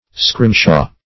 Scrimshaw \Scrim"shaw`\, v. t.